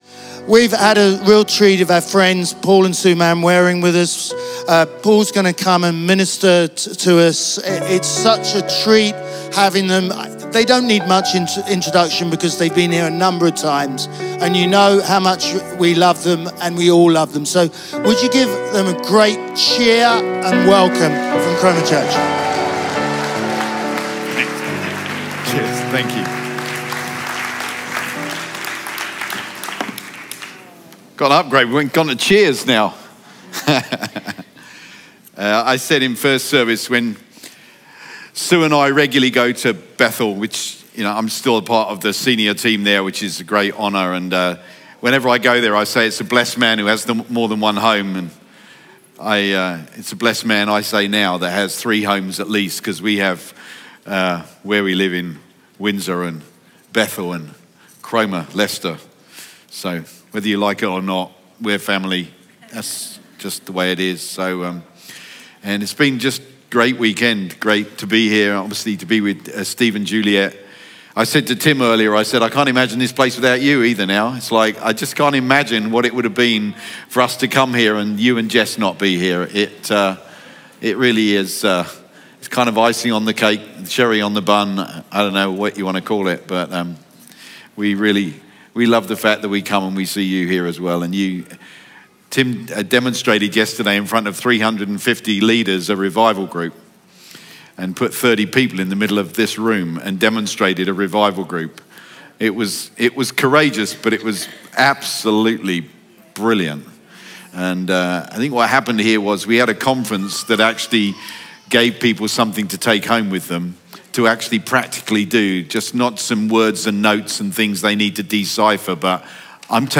Chroma Church - Sunday Sermon 2023 -A Truth Encounter Jan 16 2023 | 00:37:59 1x 00:00 / 00:37:59 Subscribe Share RSS Feed Share Link Embed